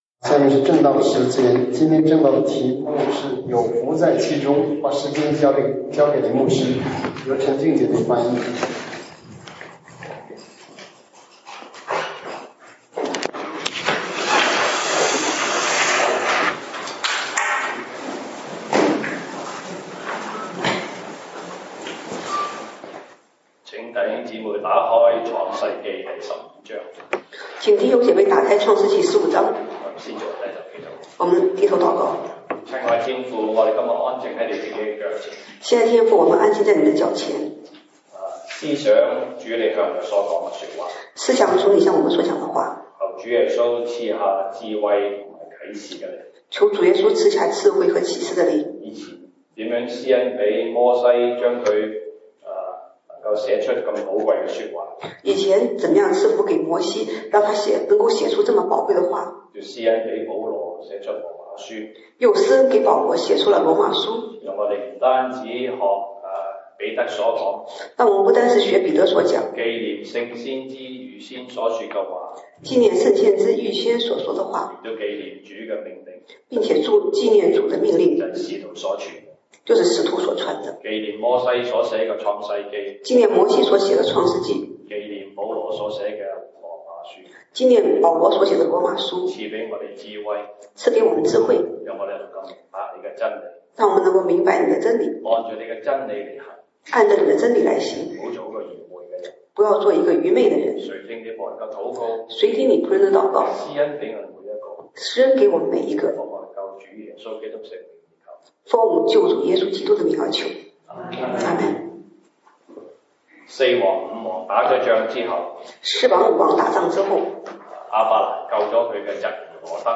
西堂證道(粵語/國語) Sunday Service Chinese: 有寶貝在其中
Passage: 羅馬書 Romans 9:1-9 Service Type: 西堂證道(粵語/國語) Sunday Service Chinese